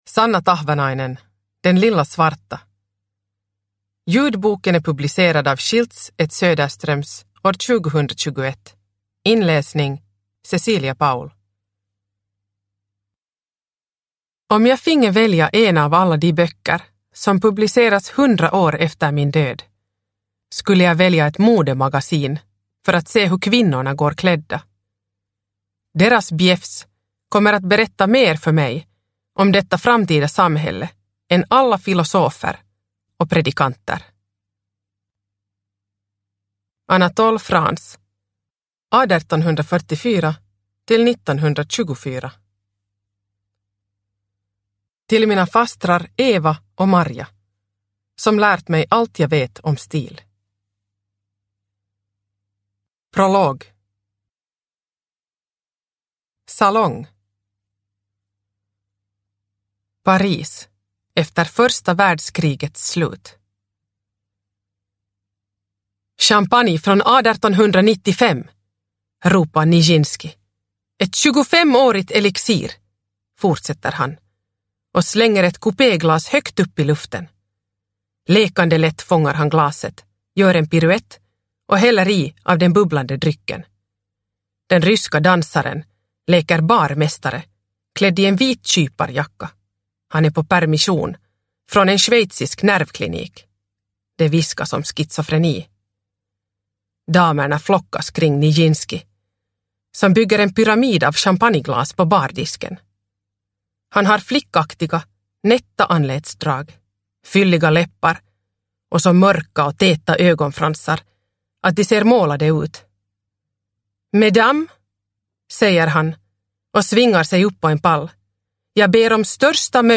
Den lilla svarta – Ljudbok – Laddas ner